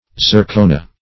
zircona - definition of zircona - synonyms, pronunciation, spelling from Free Dictionary Search Result for " zircona" : The Collaborative International Dictionary of English v.0.48: Zircona \Zir"co*na\, n. [NL.]